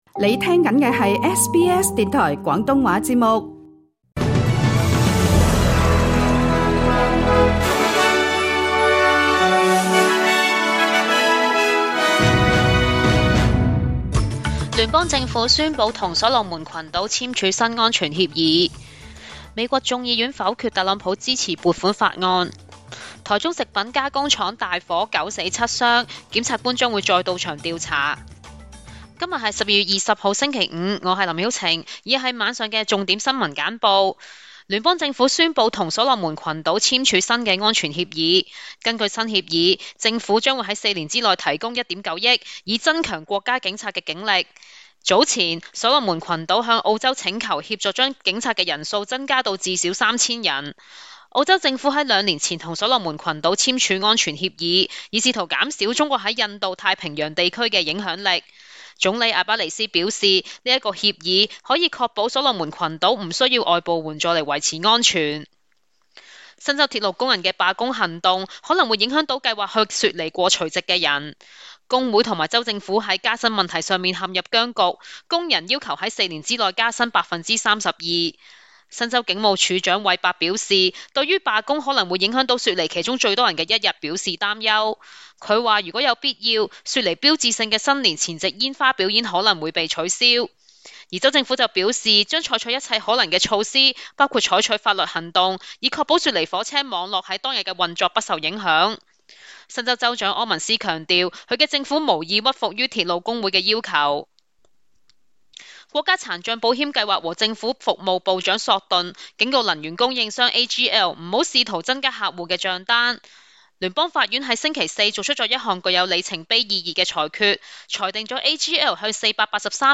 請收聽本台為大家準備的每日重點新聞簡報。
SBS 廣東話晚間新聞